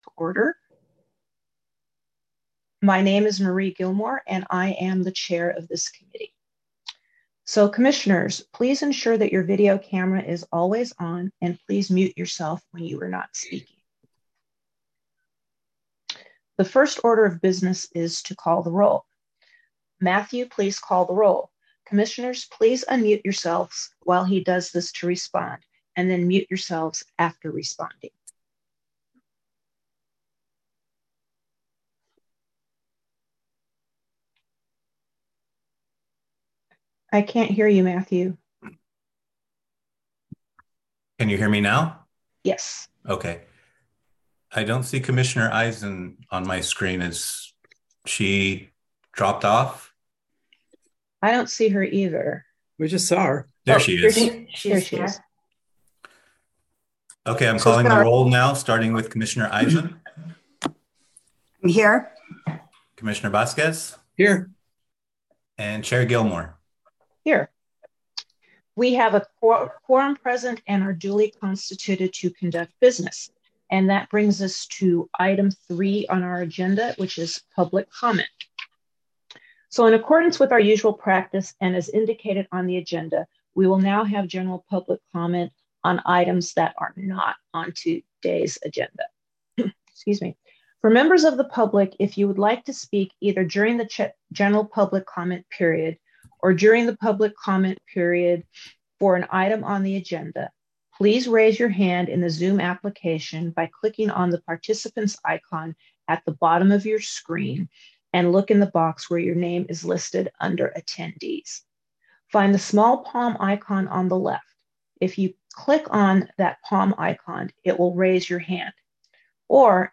February 22, 2023 Enforcement Committee Meeting | SF Bay Conservation & Development